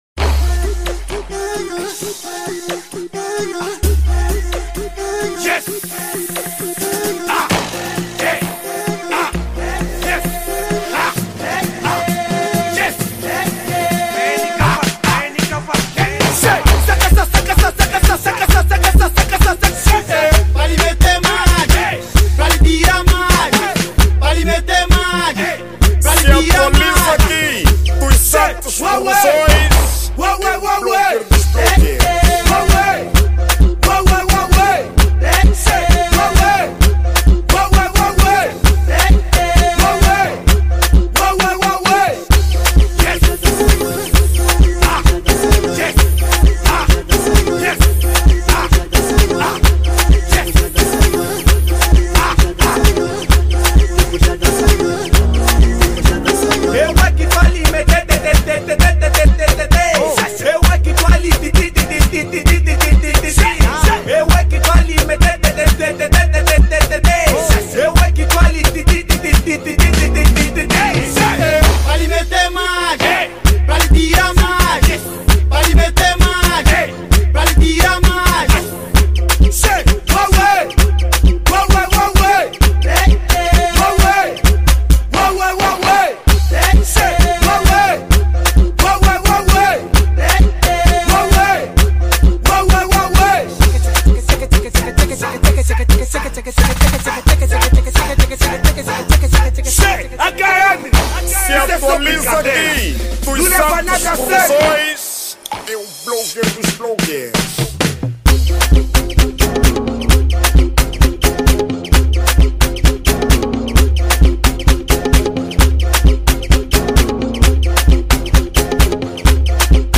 Categoria: Afro House